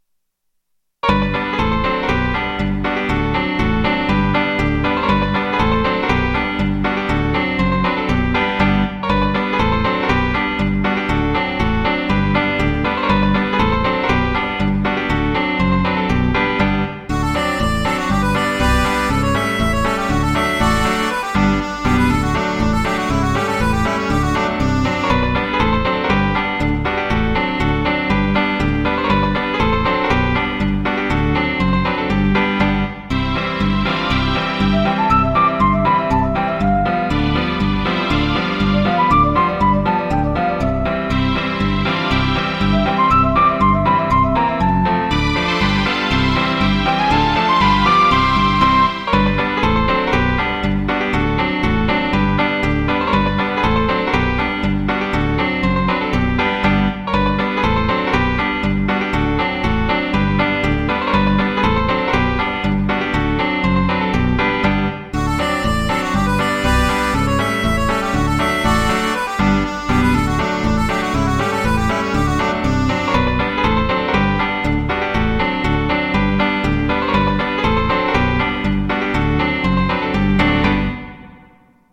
Полька